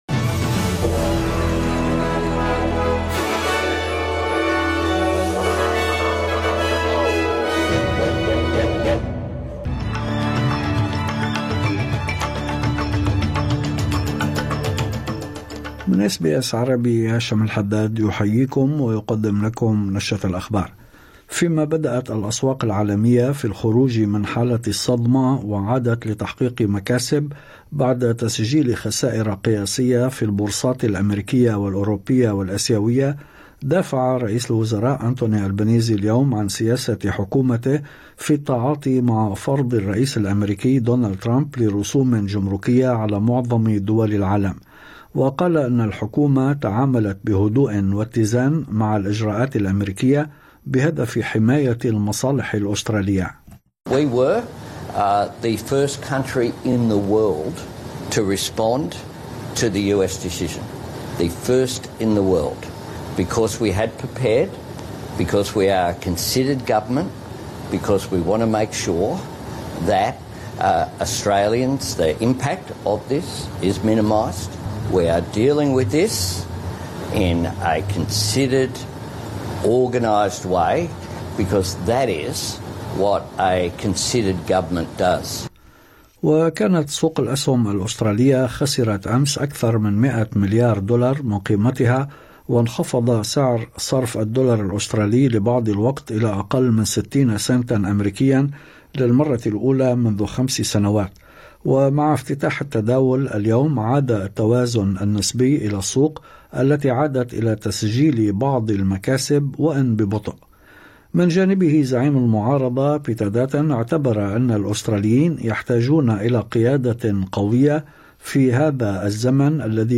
نشرة أخبار الظهيرة 08/04/2024